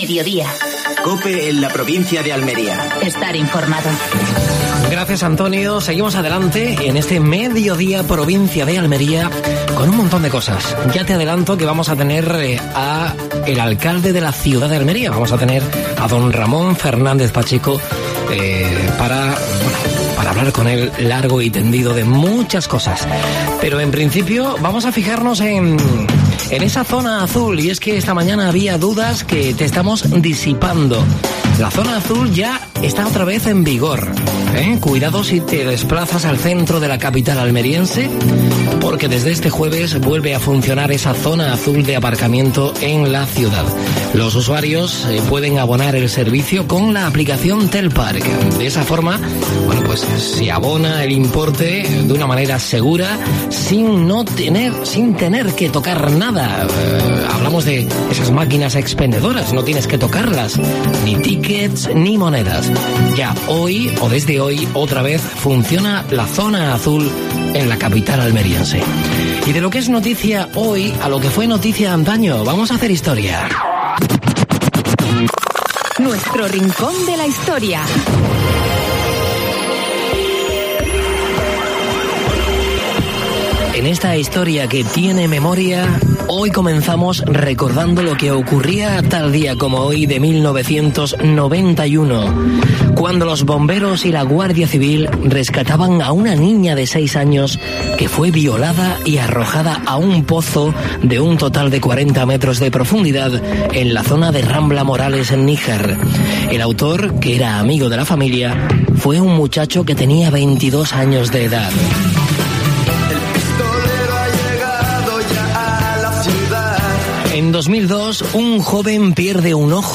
AUDIO: Actualidad en Almería. Entrevista a Ramón Fernández-Pacheco (alcalde de Almería). Última hora deportiva.